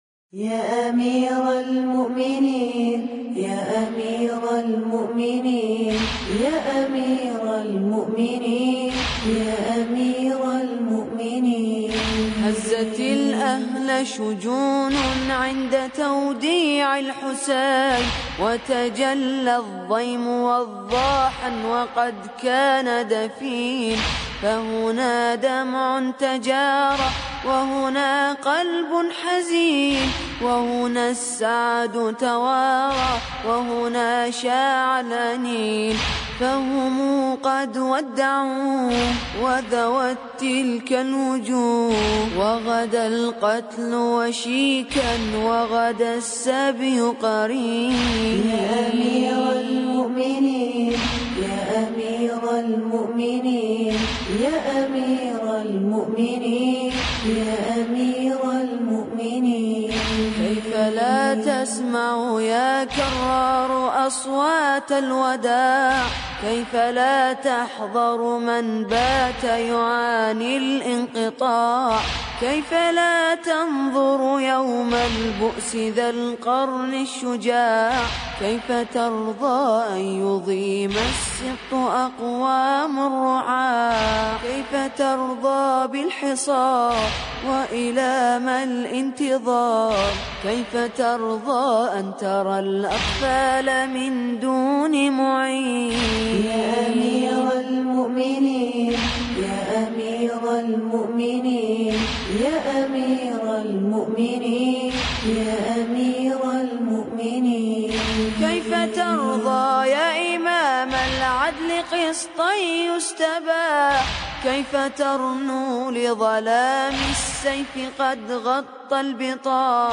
فرقة ذوالفقار
مراثي الامام علي (ع)